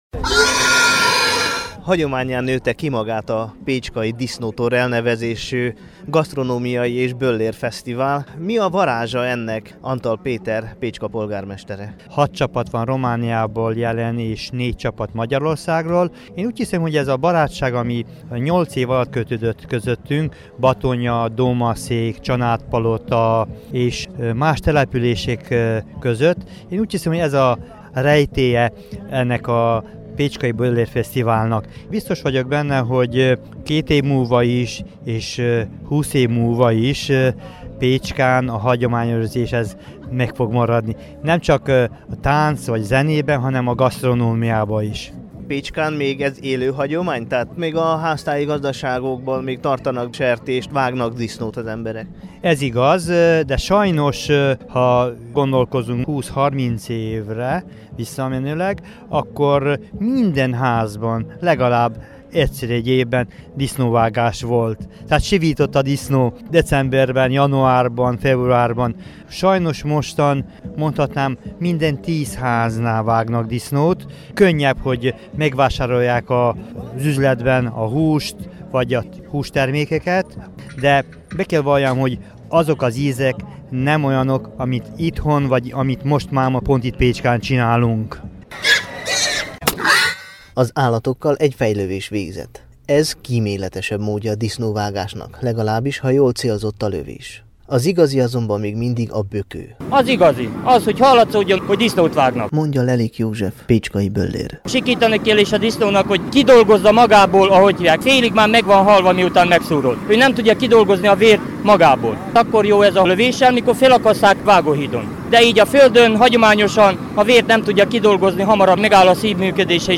Pecskai_disznotor_2017_MR.mp3